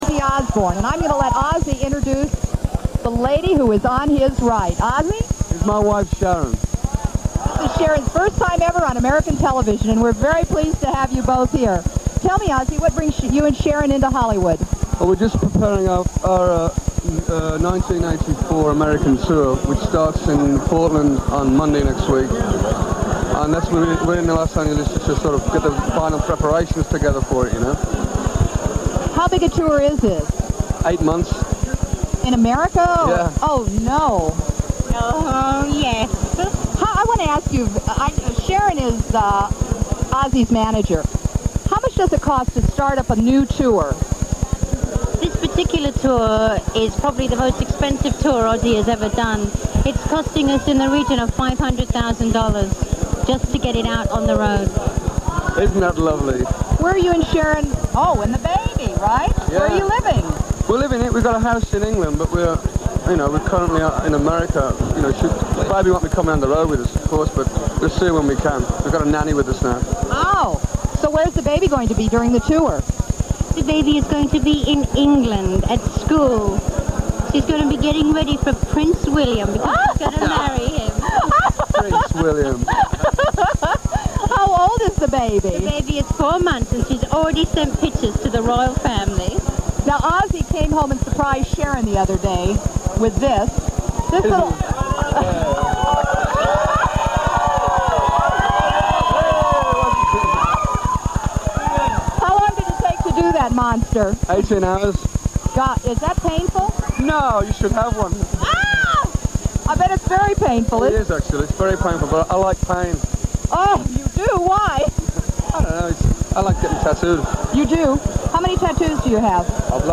OZZY AND SHARON'S FIRST INTERVIEW